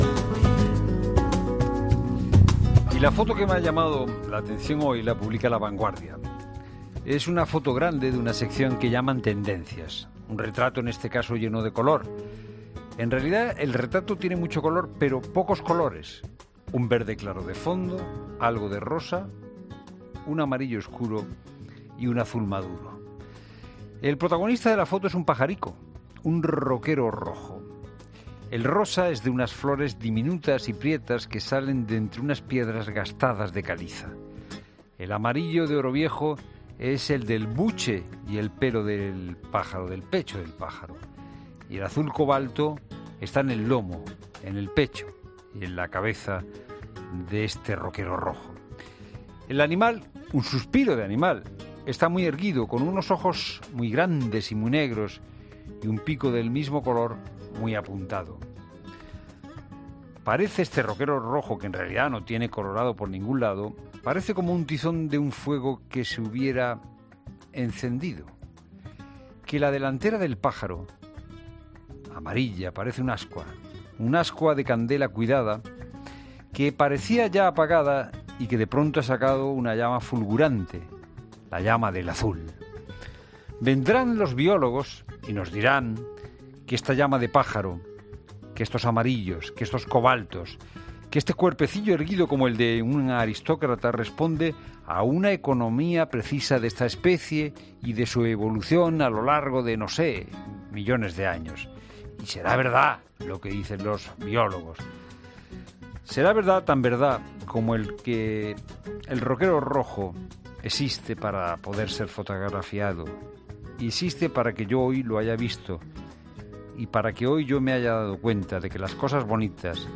Fernando de Haro cierra el programa con la foto del día, la de un roquero rojo publicada en La Vanguardia